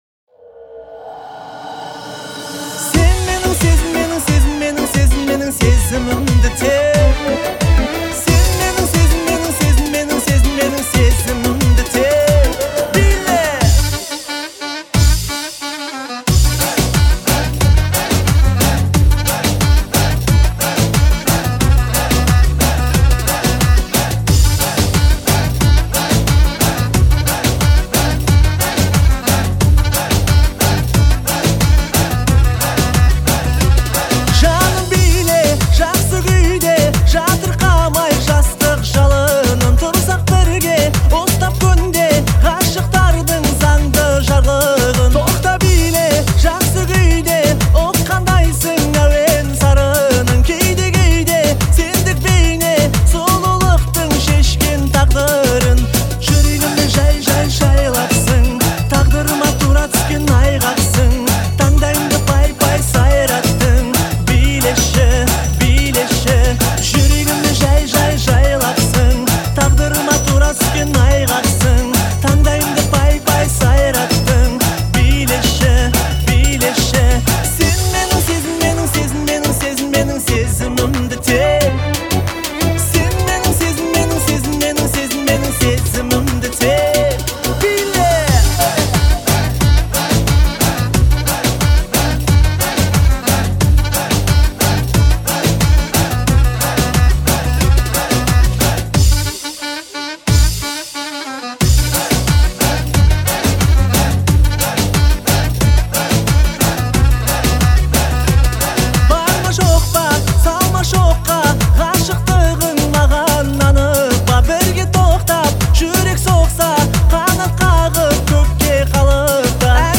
это эмоциональная песня в жанре казахского поп-фолка